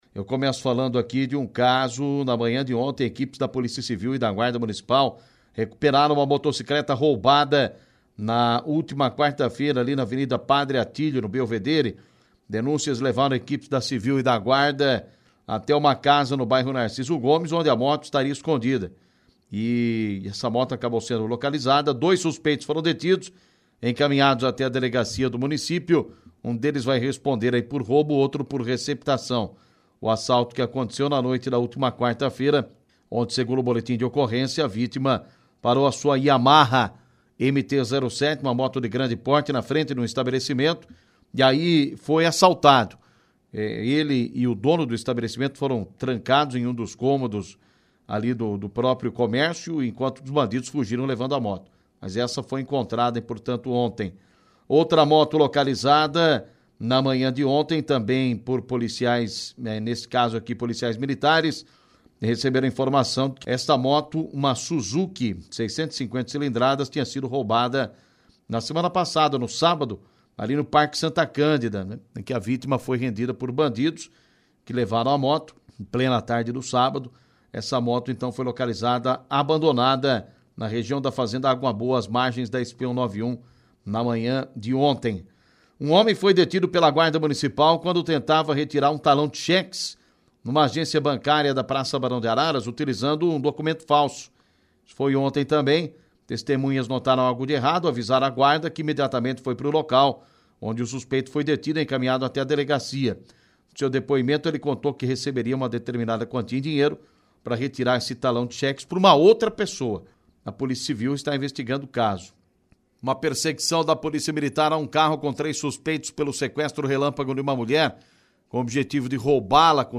Clique no link abaixo e ouça as principais notícias ocorridas em Araras e região na voz do repórter policial